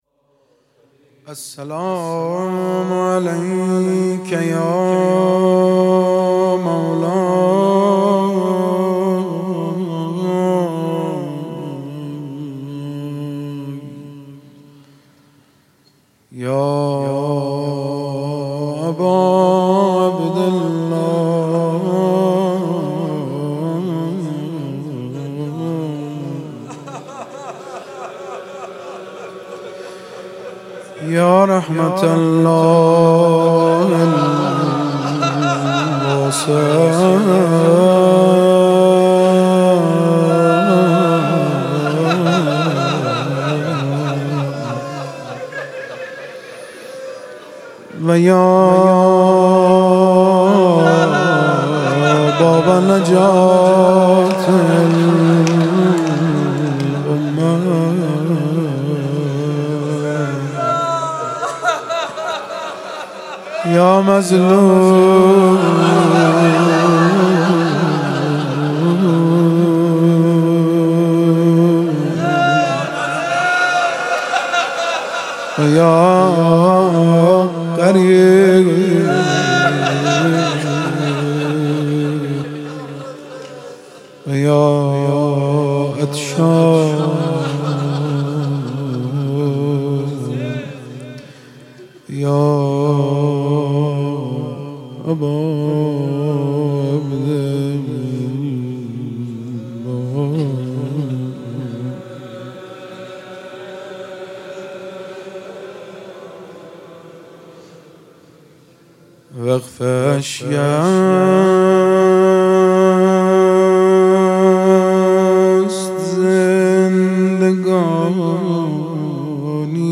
محرم98 - روضه - وقف اشک است زندگانی من